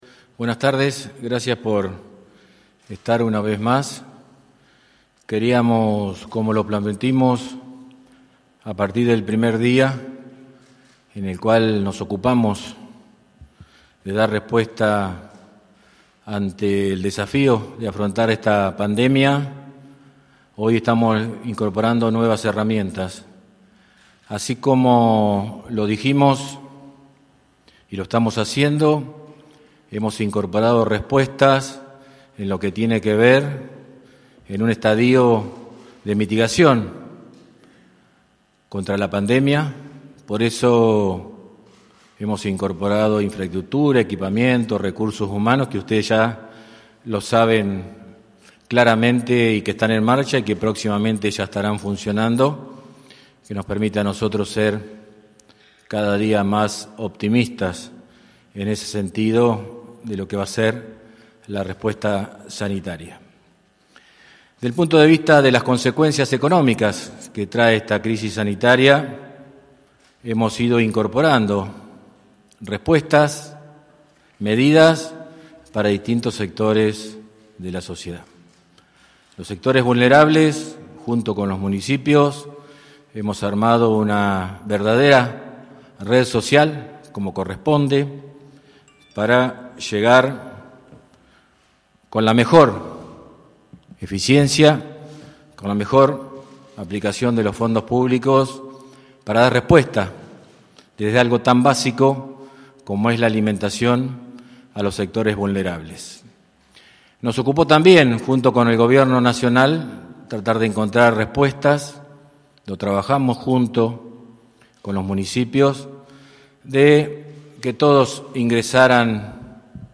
En rueda de prensa, el gobernador Sergio Ziliotto, anunció que el Banco de La Pampa dispondrá una cartera de crédito de 1.000 millones de pesos a tasa 0%, para Micro, Pequeñas y Medianas Empresas que podrán destinar al pago de salarios de sus trabajadores o para adquirir capital de trabajo. Lo empezarán a devolver a los 90 días.